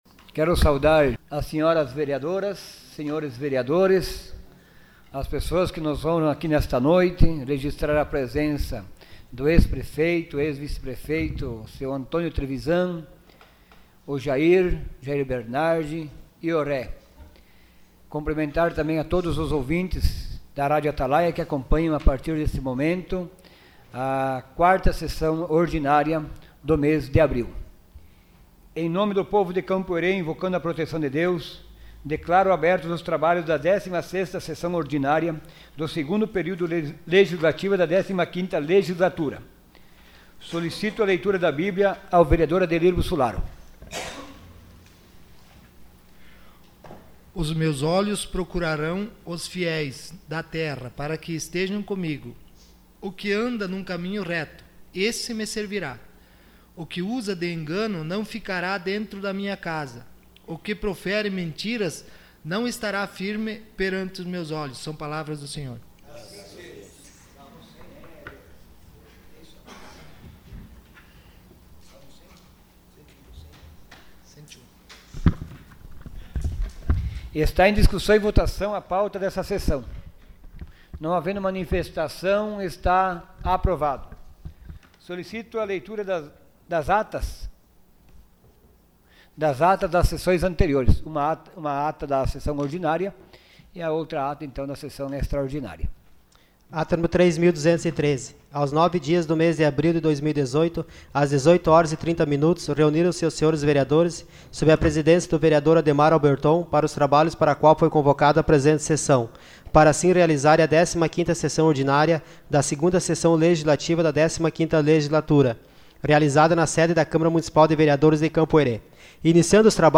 Sessão Ordinária dia 25 de abril de 2018.